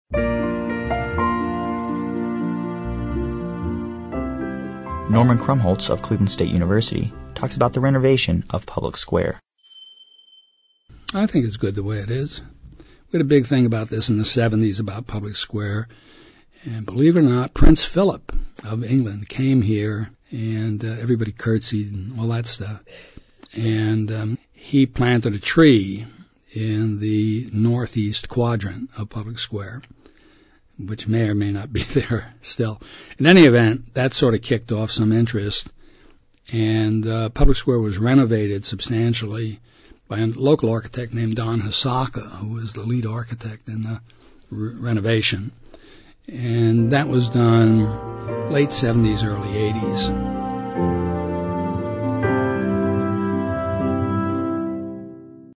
Source: Cleveland Regional Oral History Collection